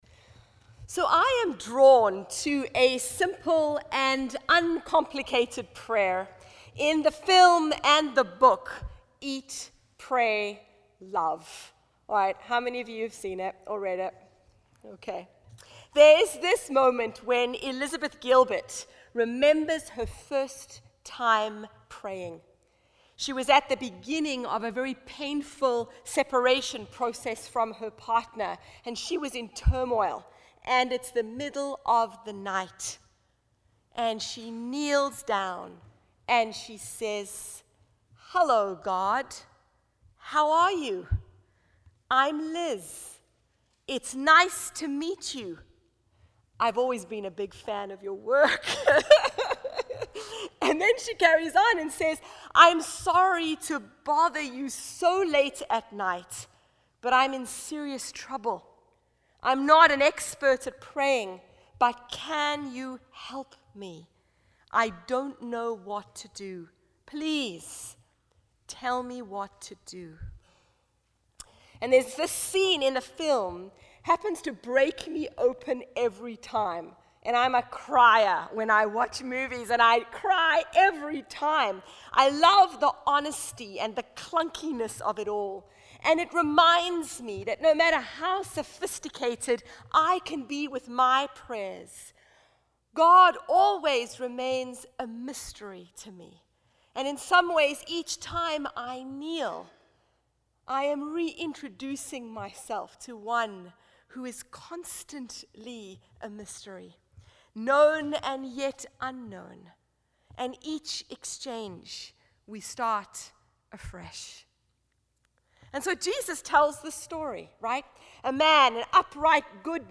Past Services | St. Catherine's Church